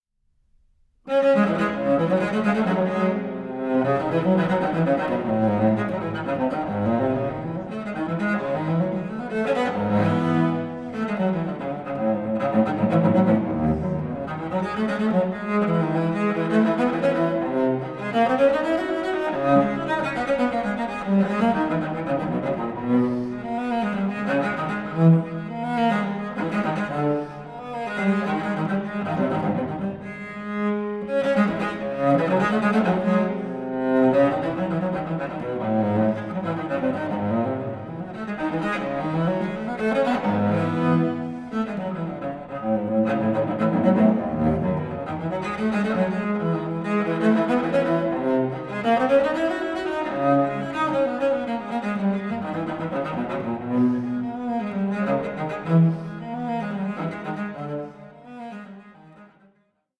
baroque cello and cello piccolo